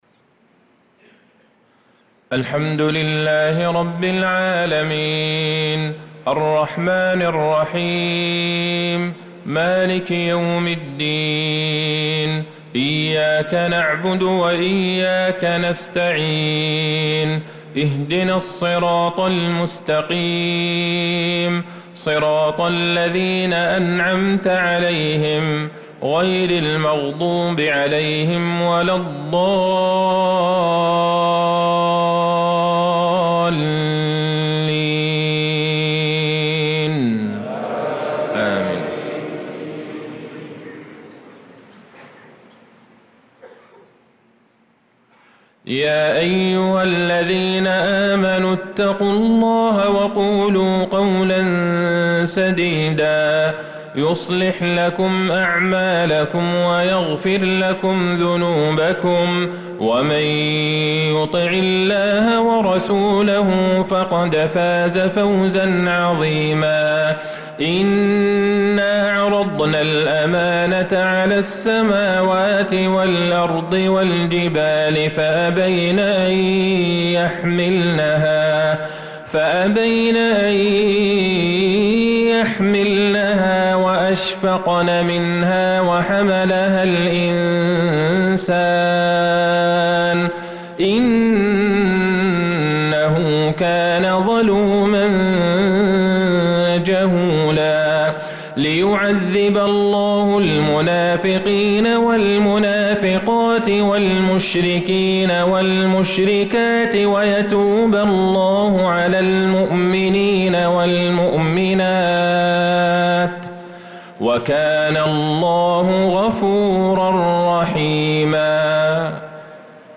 صلاة المغرب للقارئ عبدالله البعيجان 16 ذو الحجة 1445 هـ
تِلَاوَات الْحَرَمَيْن .